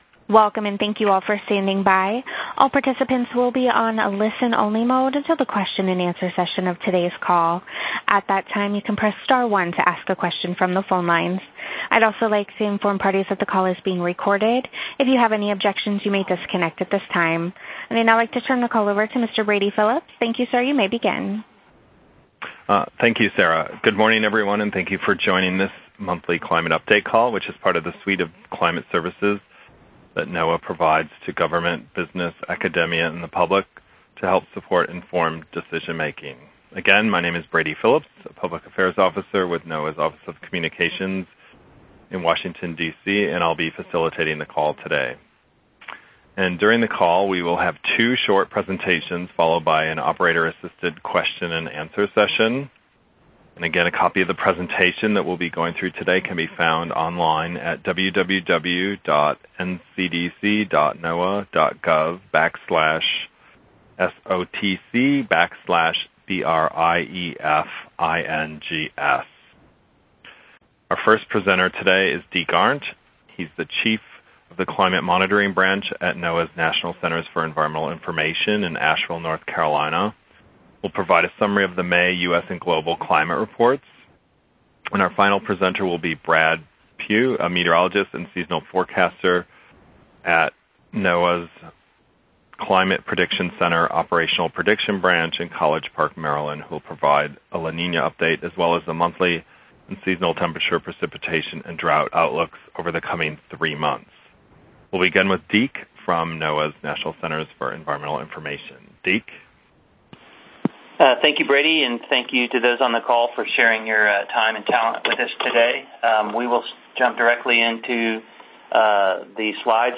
Experts to hold media teleconference on climate conditions for U.S. and globe